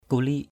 /ku-li:ʔ/ (d.) tu viện